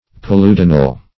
Meaning of paludinal. paludinal synonyms, pronunciation, spelling and more from Free Dictionary.
Paludinal \Pal`u*di"nal\, a. Inhabiting ponds or swamps.